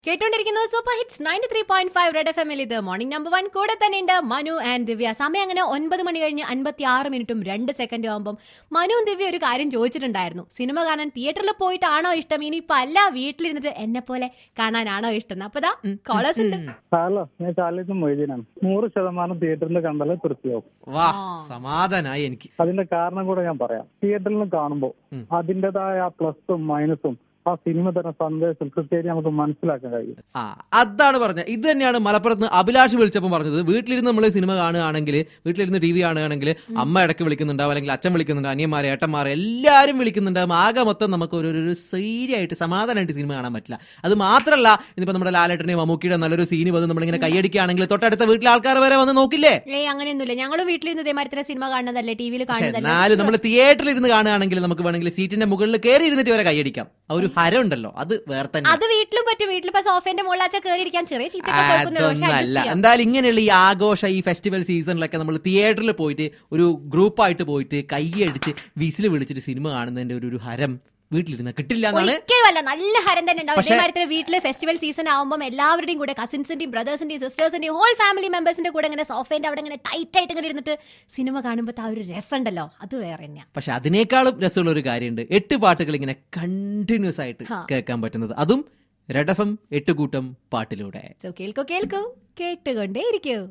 WHERE DO U PREFER TO WATCH MOVIE, AT HOME OR THEATER. WITH CALLER.